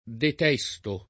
detestare v.; detesto [ det $S to ]